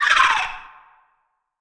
Sfx_creature_babypenguin_shudder_02.ogg